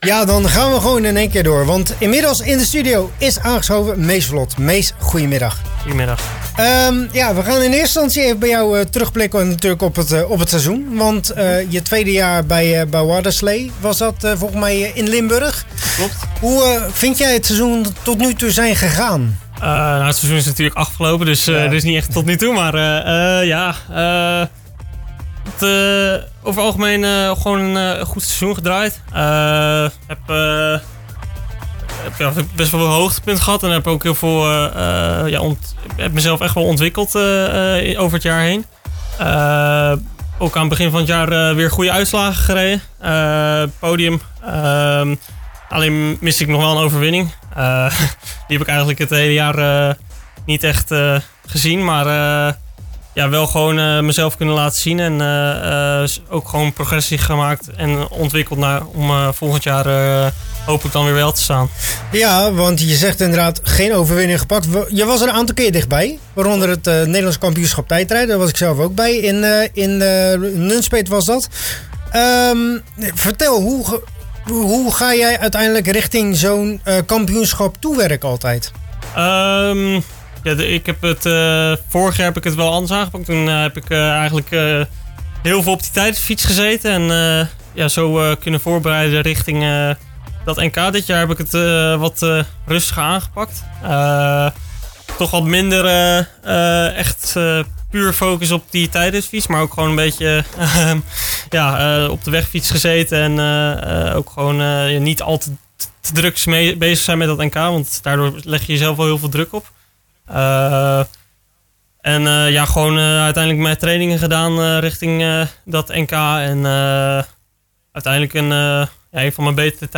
In de studio van Radio Capelle was aangeschoven